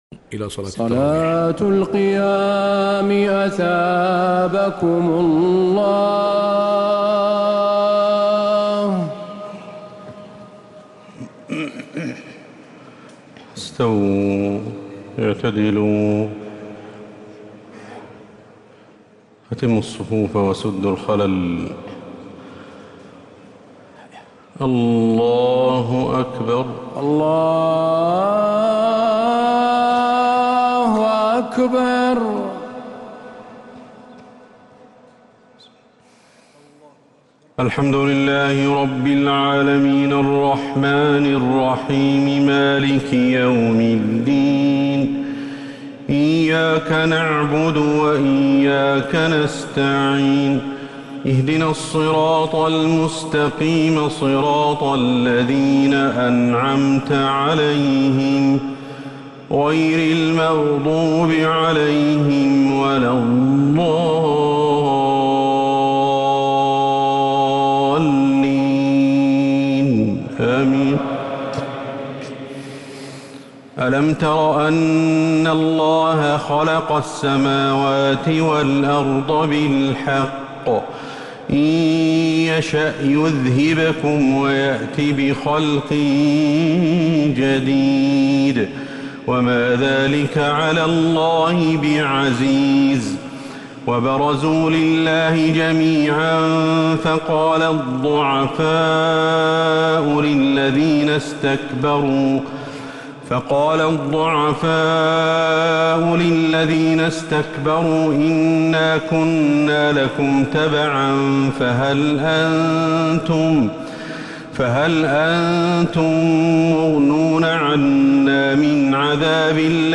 تراويح ليلة 18 رمضان 1447هـ من سورتي إبراهيم (19-52) و الحجر كاملة | Taraweeh 18th night Ramadan 1447H Surat Ibrahim and Al-Hijr > تراويح الحرم النبوي عام 1447 🕌 > التراويح - تلاوات الحرمين